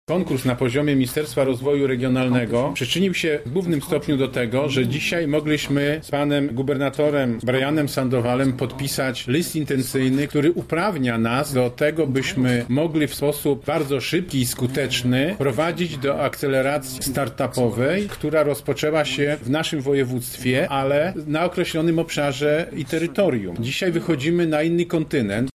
-To szansa dla młodych naukowców na międzynarodową karierę – dodaje Marszałek Województwa Sławomir Sosnowski: